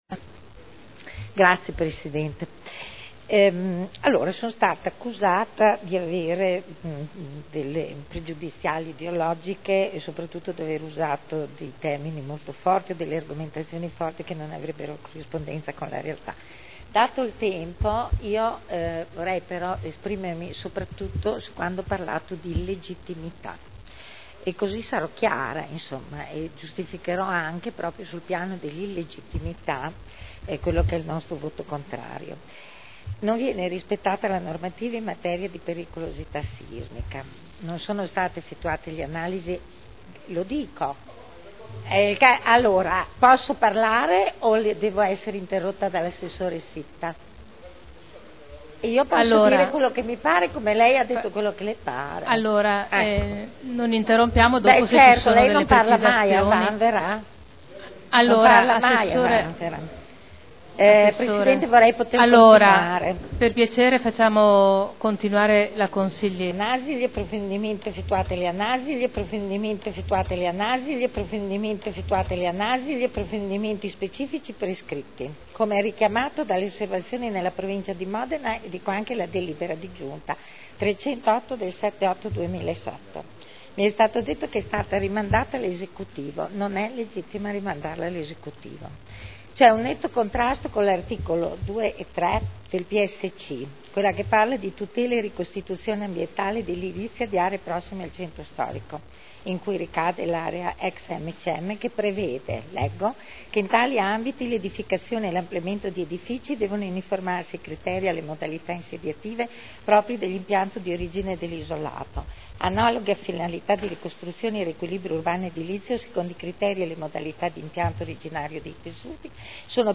Eugenia Rossi — Sito Audio Consiglio Comunale
Dichiarazione di voto. Piano Urbanistico Attuativo – Variante al Piano particolareggiato di iniziativa pubblica area ex sede A.M.C.M. in variante al POC – Controdeduzioni alle osservazioni e approvazione ai sensi dell’art. 35 della L.R. 20/2000 (Commissione consiliare dell’1 e 17 dicembre 2009)